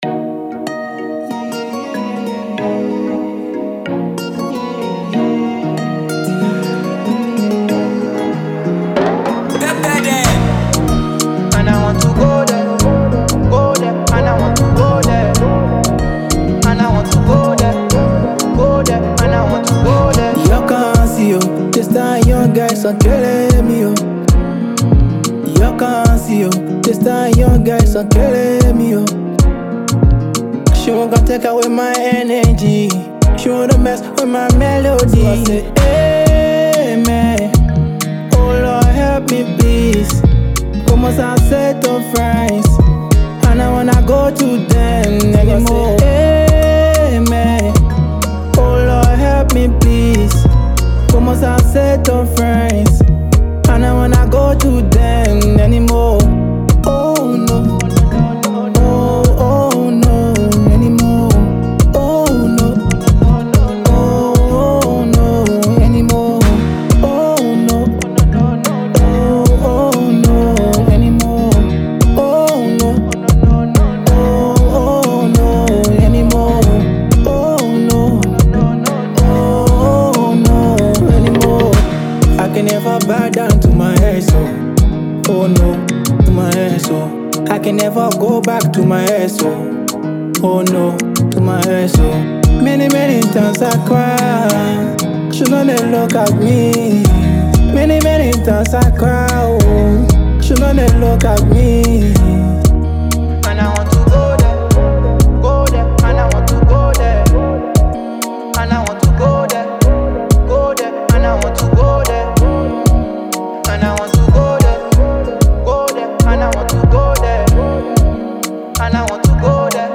reflective new single
With a calm delivery and honest lyrics
smooth, restrained production that leaves room for feeling
The simplicity works in its favor